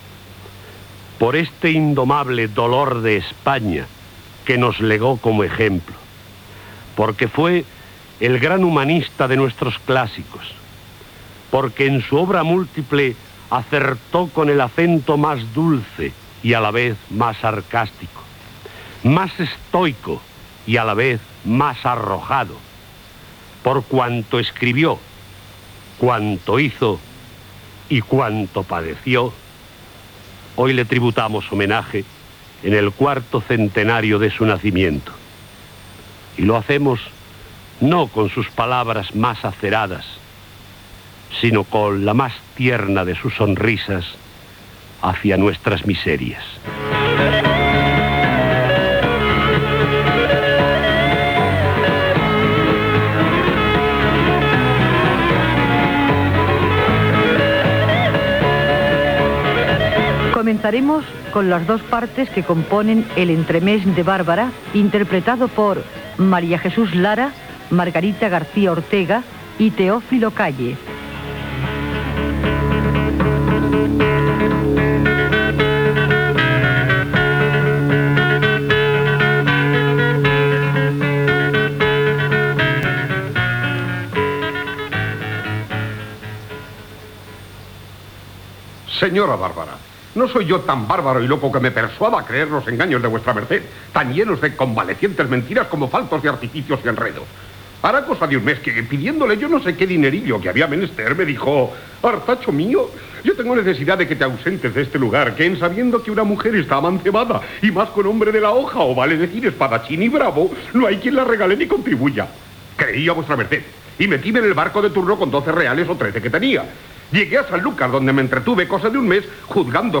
Comentari i fragment de la obra "Bárbara" de "Los entremeses" de Francisco de Quevedo on hi participen els actors
Cultura